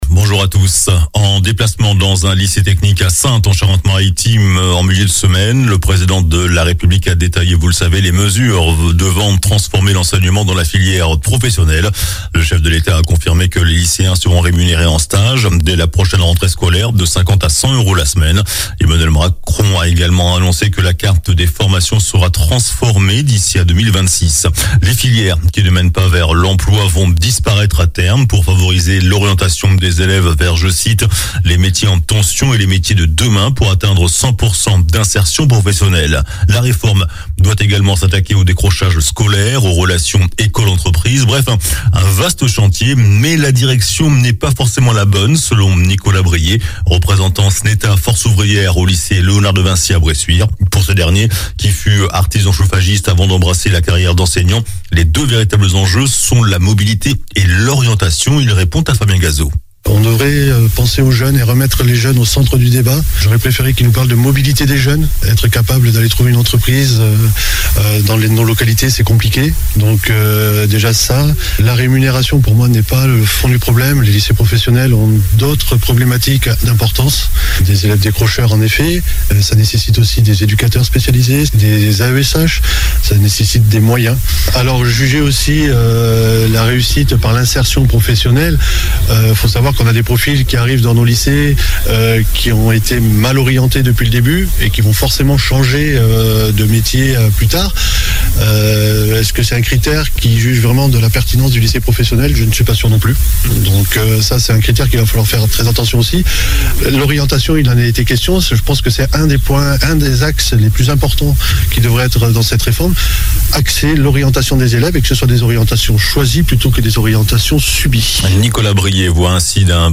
JOURNAL DU SAMEDI 06 MAI